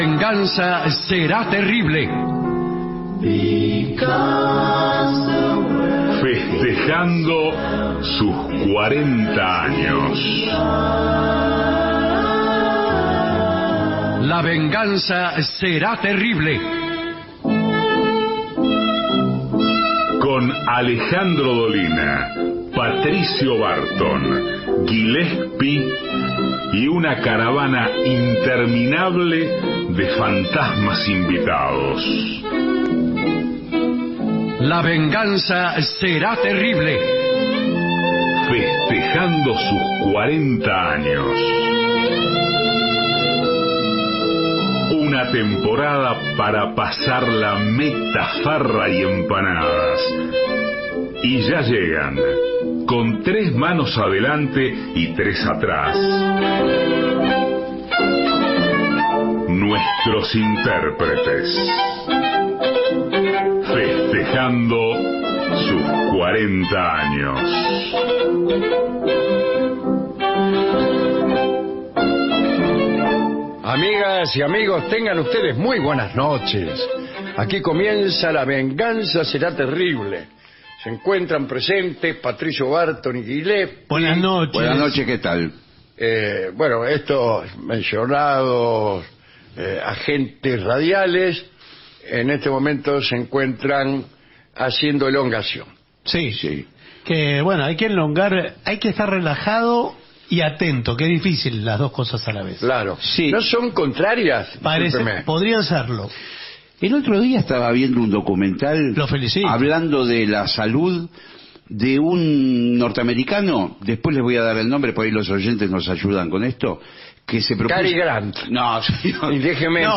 todo el año festejando los 40 años Estudios AM 750 Alejandro Dolina